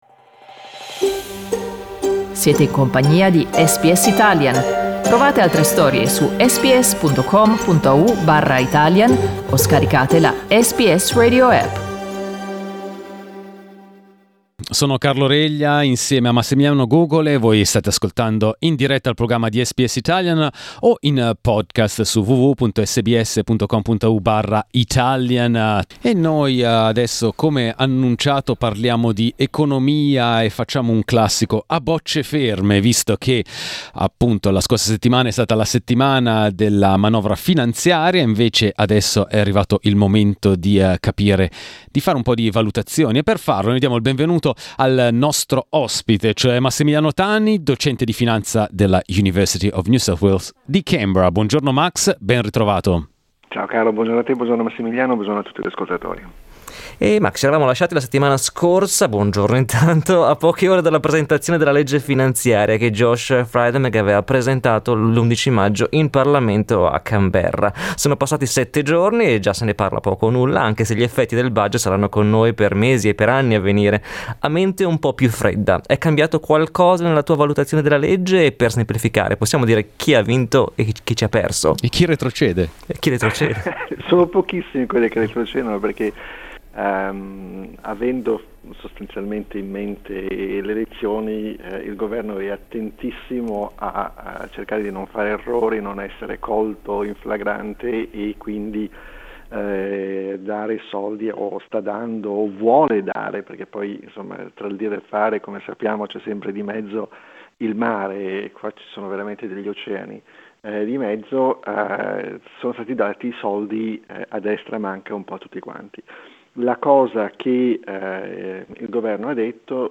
Ascolta la sua intervista a SBS Italian.